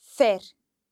When R is not the first letter in a word and occurs next to a, o, or u, it is considered broad, and can be heard in fear (a man):